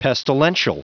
Prononciation du mot pestilential en anglais (fichier audio)
Prononciation du mot : pestilential